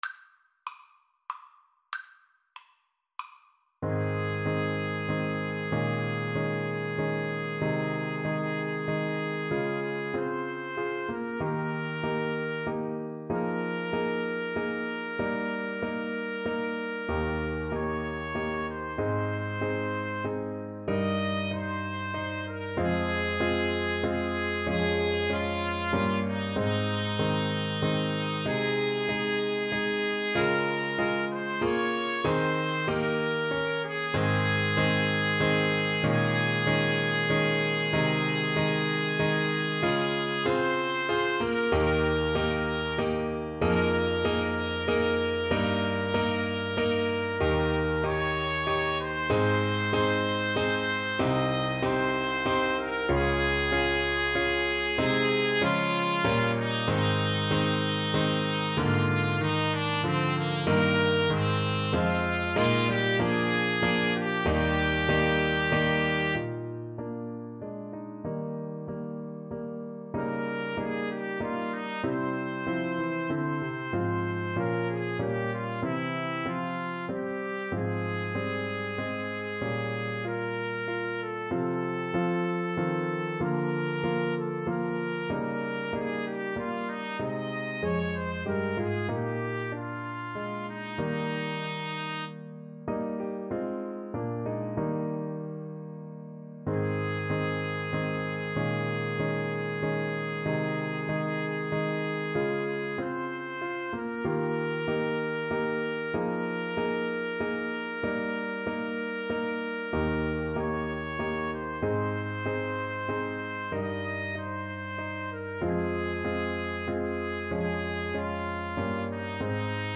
=95 Andante